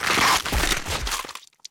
bite.wav